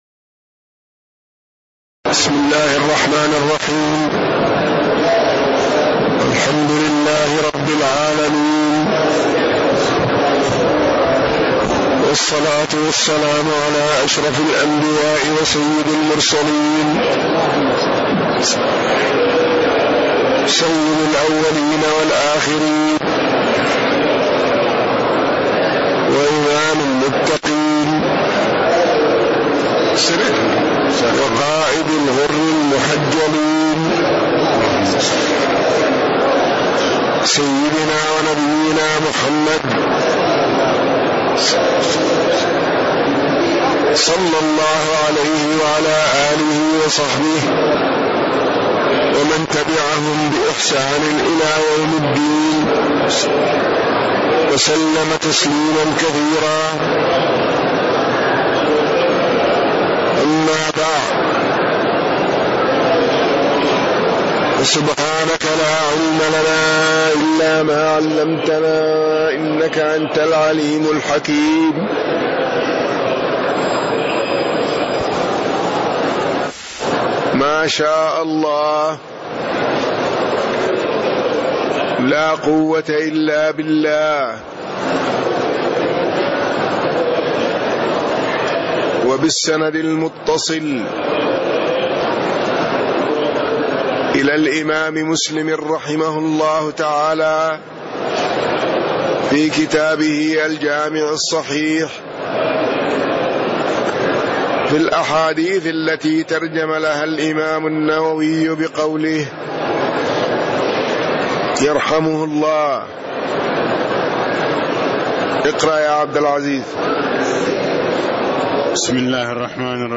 تاريخ النشر ١٨ ذو القعدة ١٤٣٦ هـ المكان: المسجد النبوي الشيخ